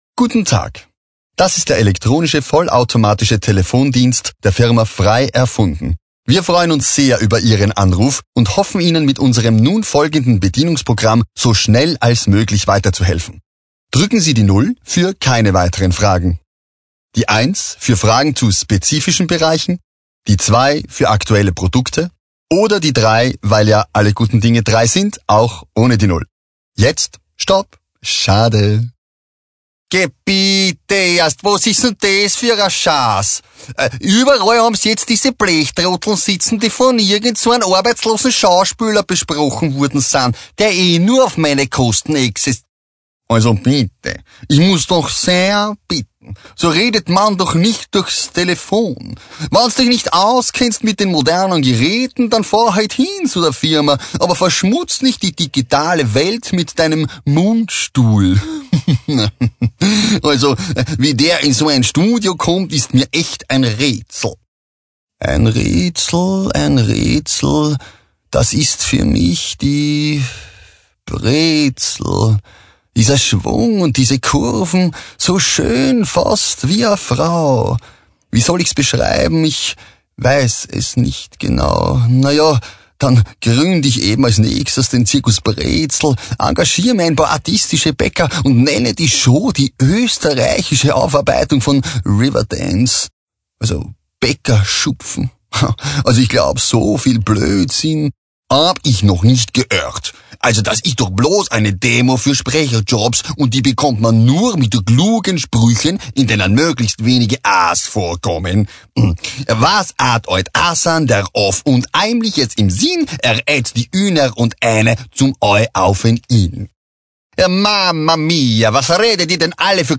SPRECHER DEMO mit verschiedenden Akzenten No01.mp3